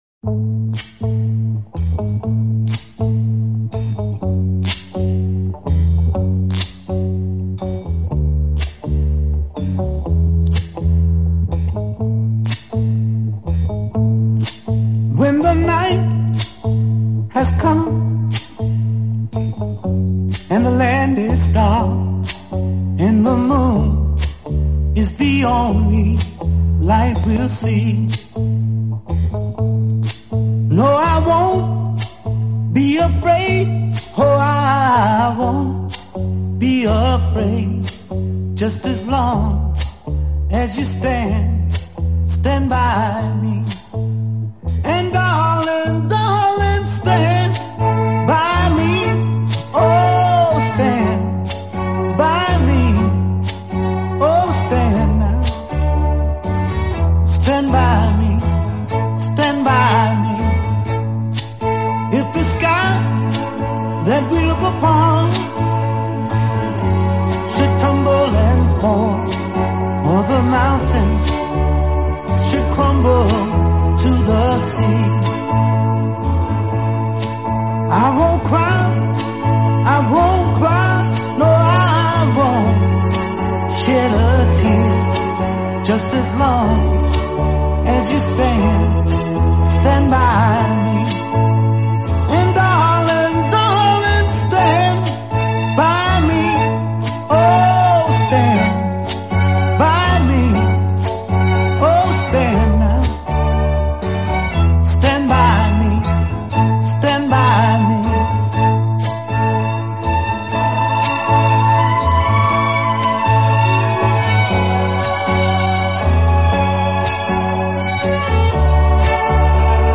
Nuestra agrupación toca las canciones de los grandes